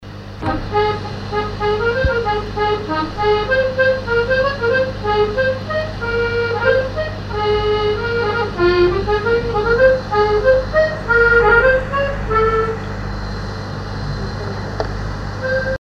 Chants brefs - A danser
scottich sept pas
accordéon diatonique
Pièce musicale inédite